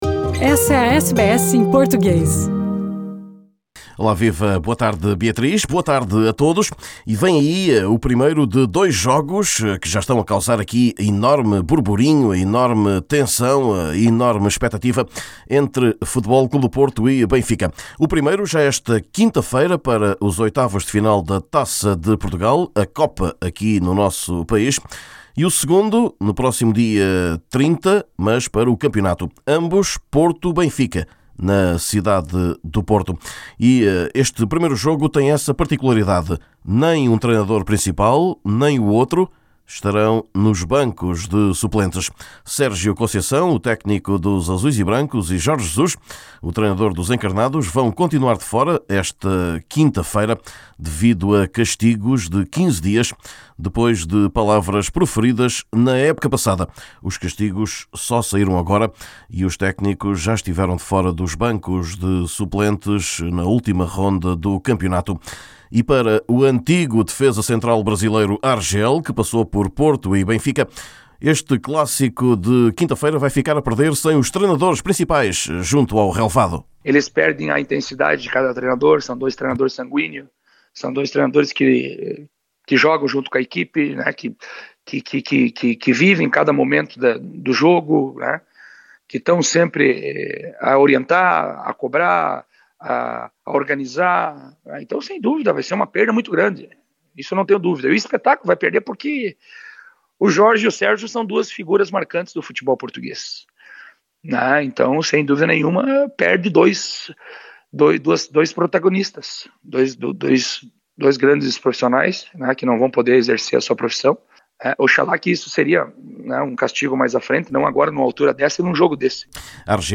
Neste boletim: Sérgio Conceição e Jorge Jesus estão castigados, durante quinze dias, por criticas à arbitragem, e falham o primeiro de dois jogos entre ambos até ao fim de 2021.